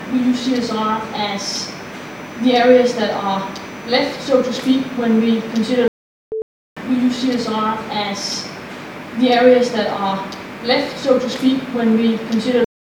I video taped a public speaker who was wearing a microport.
There is an annoying 6KHz whine towards the end of the recording, which can be removed using a notch filter
I also notched out the similar artifact tone at ~15.5KHz, but for most people that tone is too high pitched to hear.